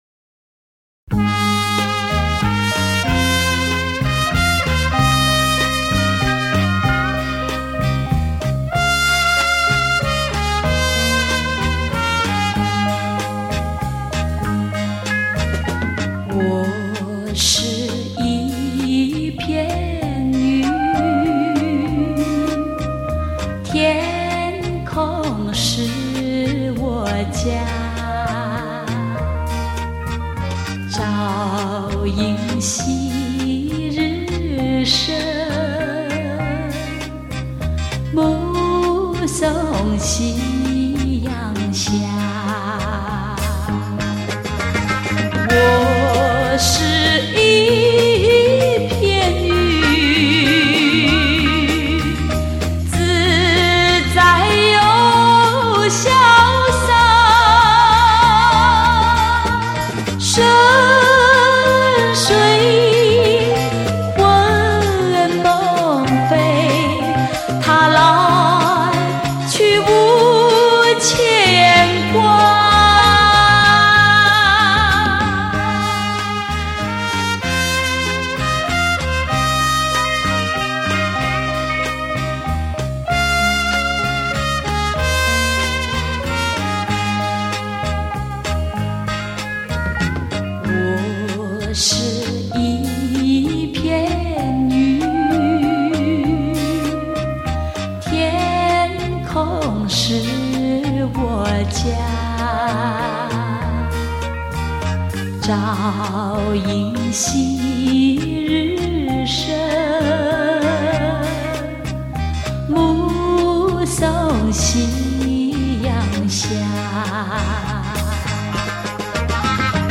音色极品版
本套全部歌曲皆数码系统重新编制 令音场透明度及层次感大为增加 并使杂讯降为最低 在任何音响组合中均可发挥最完美音色